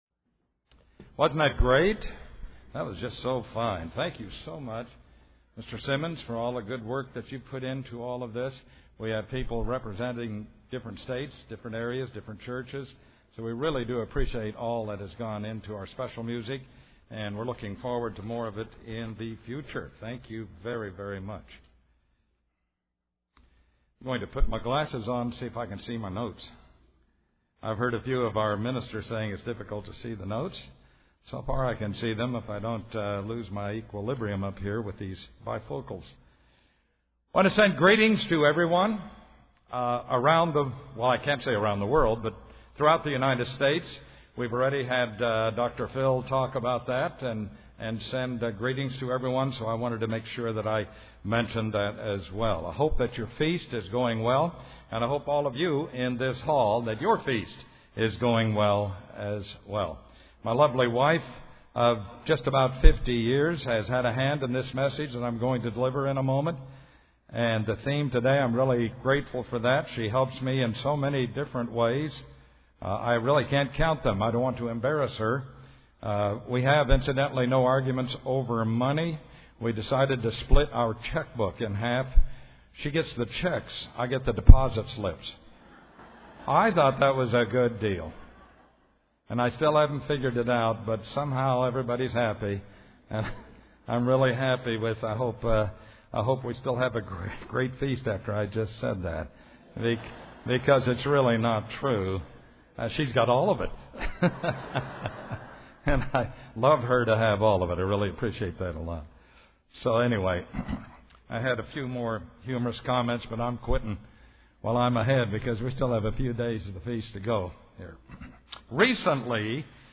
This sermon was given at the Panama City Beach, Florida 2013 Feast site.